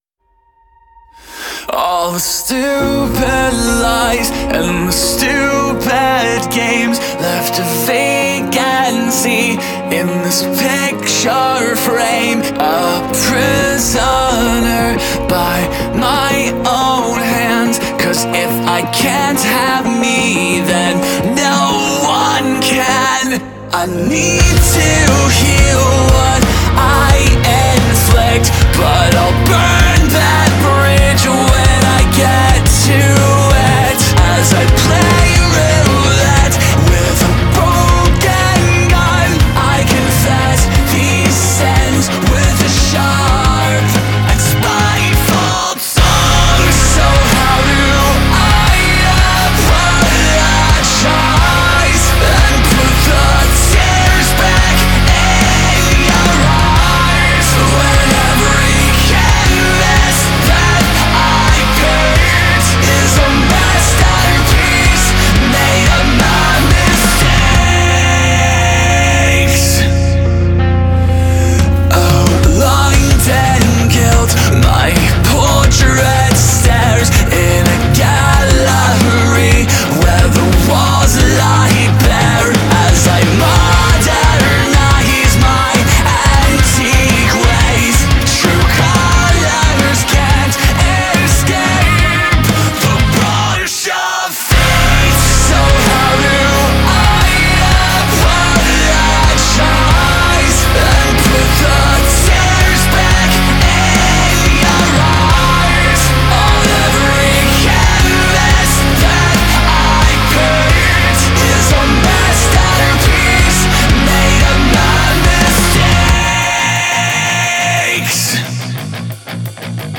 Genre: Alternative Metal / Industrial Metal / Metalcore